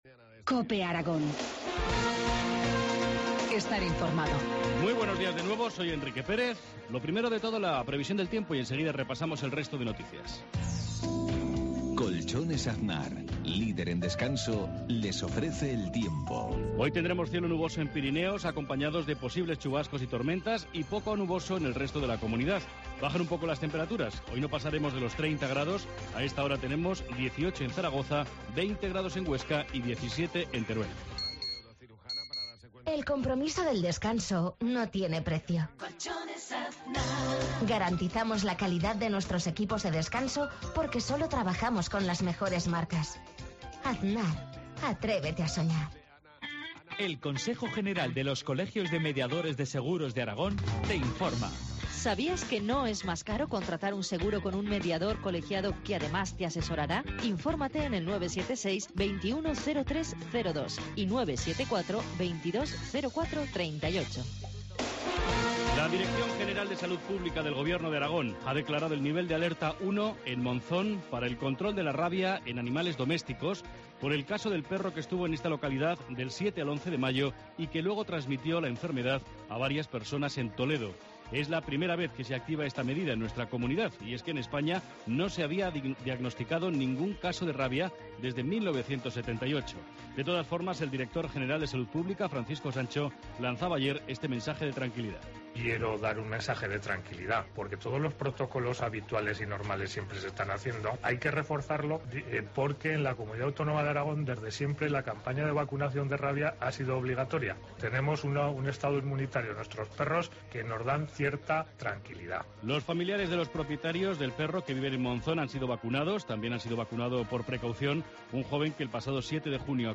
Informativo matinal, viernes 14 de junio, 7.53 horas